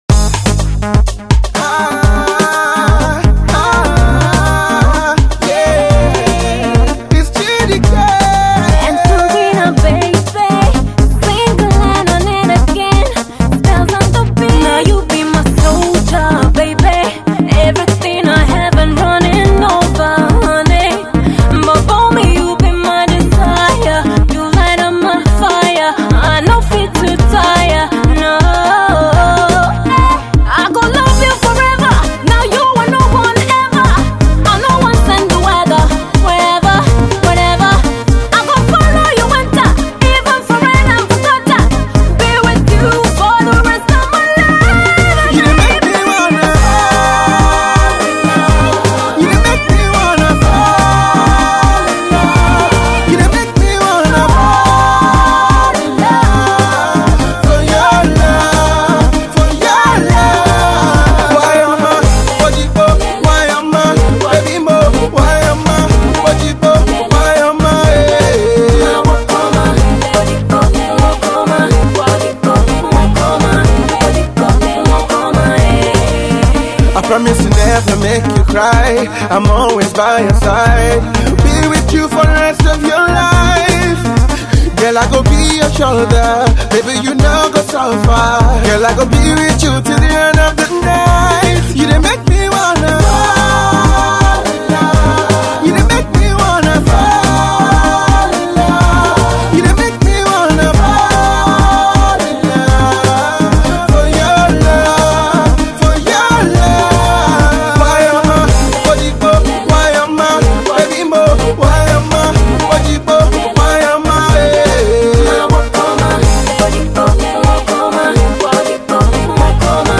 is an energetic love duet.